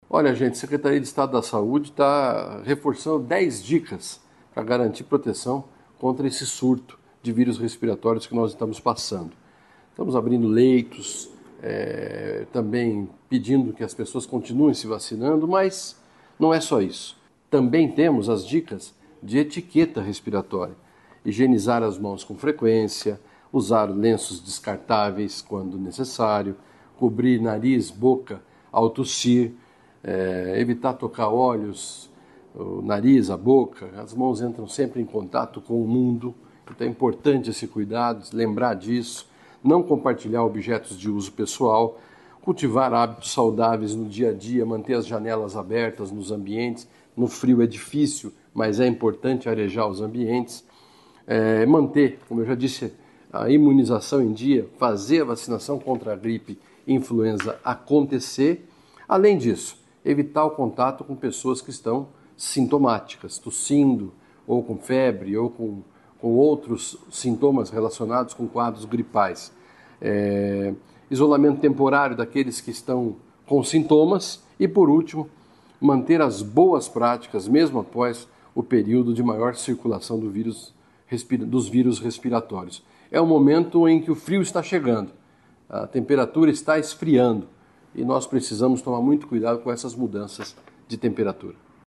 Sonora do secretário da Saúde, Beto Preto, sobre os cuidados para garantir proteção contra os vírus respiratórios